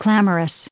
added pronounciation and merriam webster audio
829_clamorous.ogg